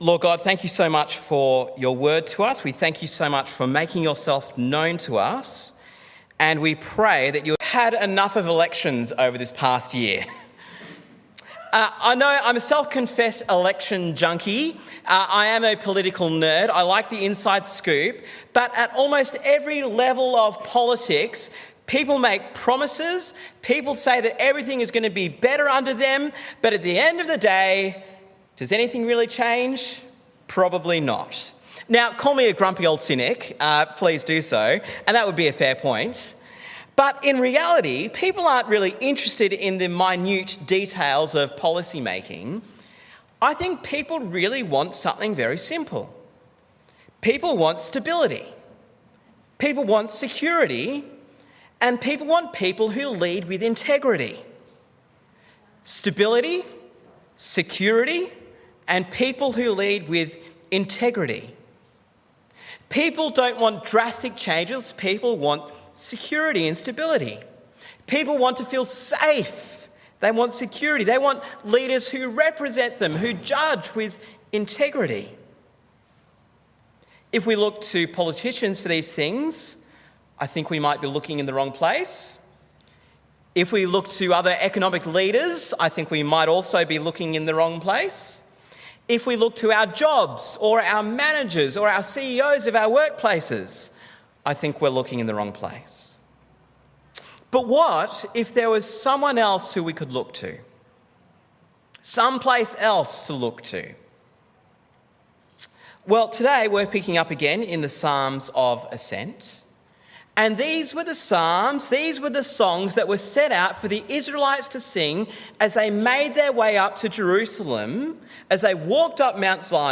Listen to the sermon on Psalm 125 in our Psalms of Ascent series.